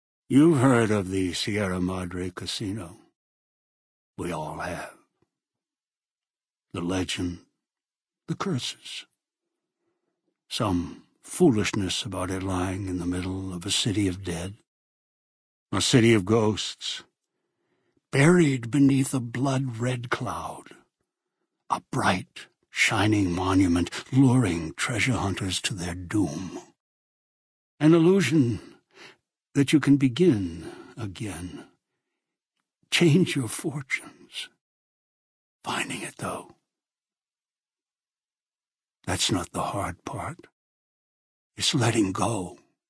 Category:Dead Money endgame narrations Du kannst diese Datei nicht überschreiben.